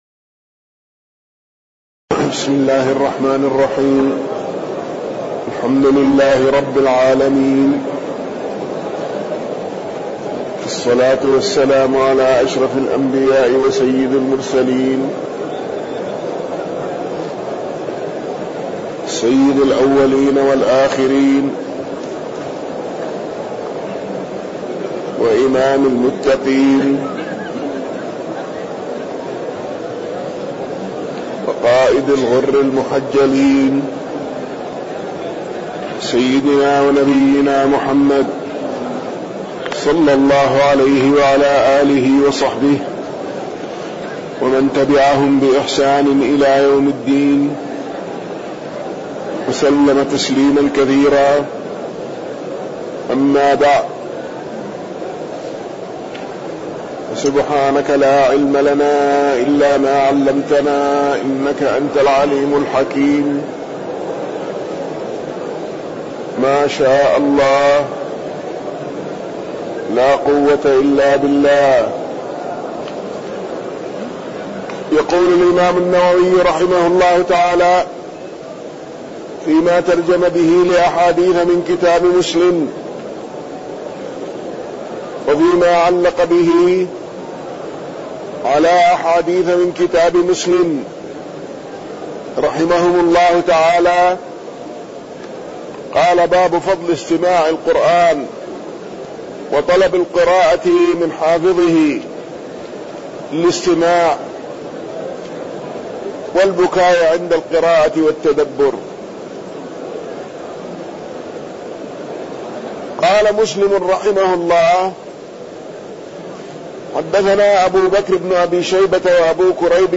تاريخ النشر ٢٠ ربيع الثاني ١٤٣١ هـ المكان: المسجد النبوي الشيخ